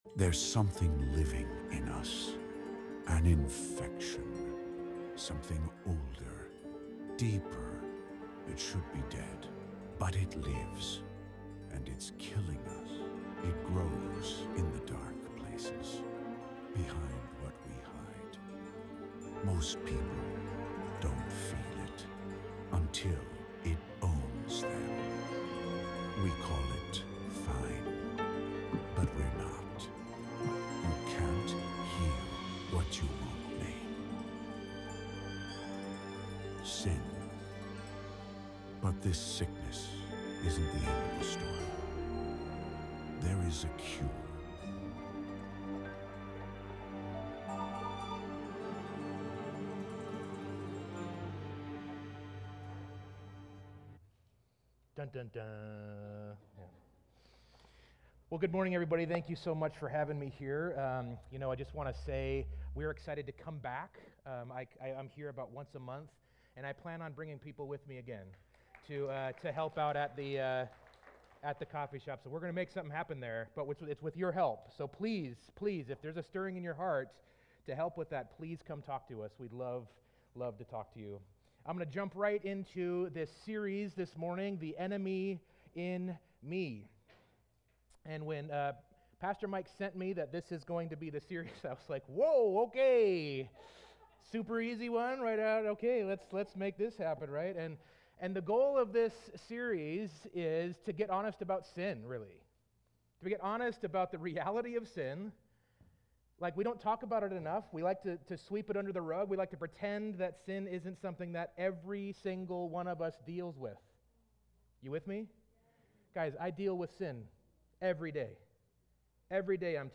This biblical teaching on Romans 7-8 provides encouragement for believers struggling with condemnation and self-worth issues.